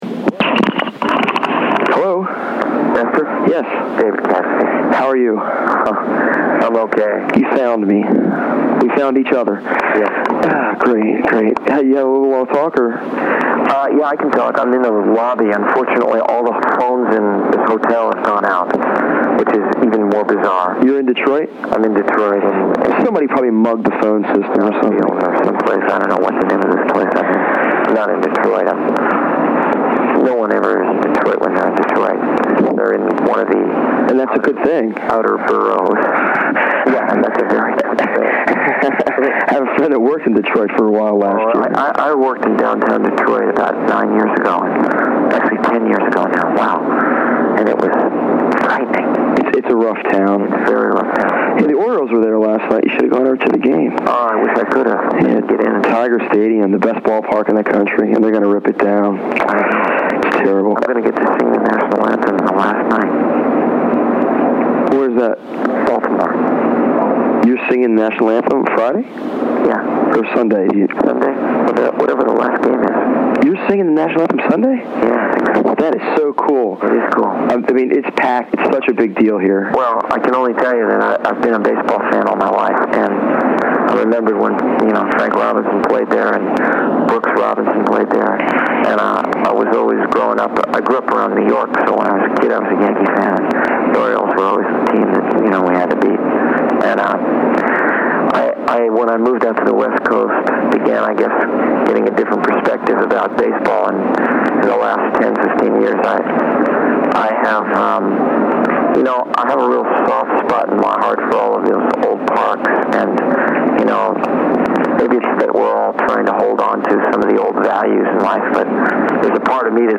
1991 Radio Interview